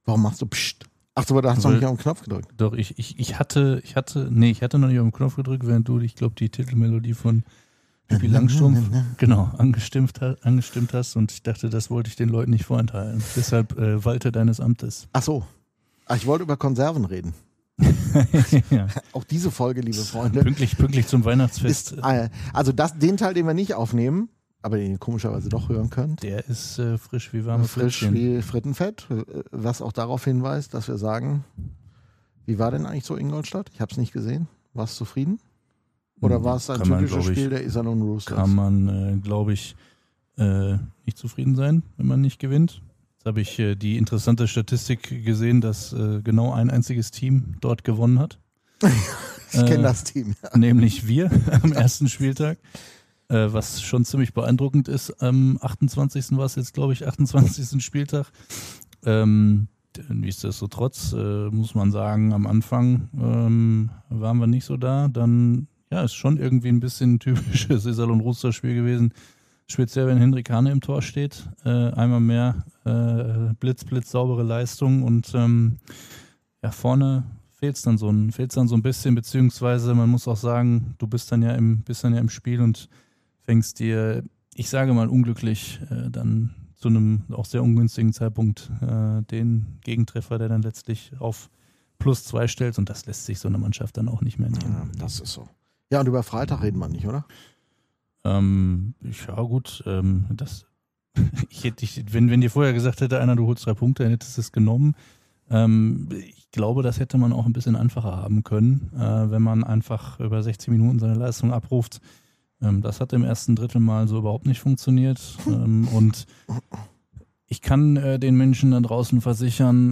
Die Fan-Welt am Seilersee. vor 4 Monaten Im Interview